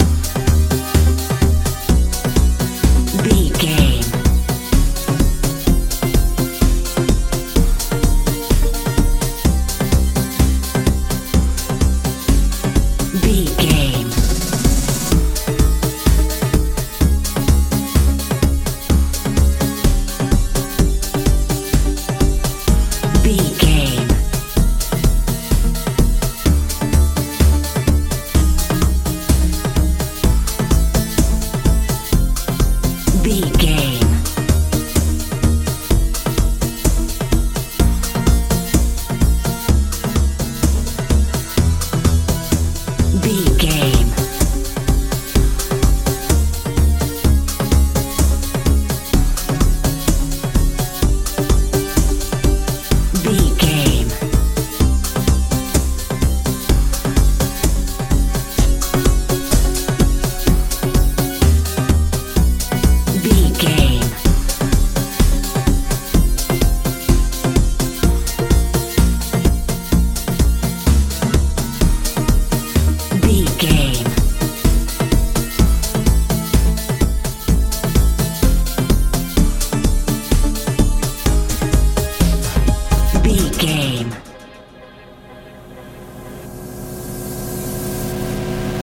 modern dance feel
Ionian/Major
magical
mystical
synthesiser
bass guitar
drums
suspense
tension